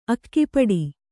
♪ akkipaḍi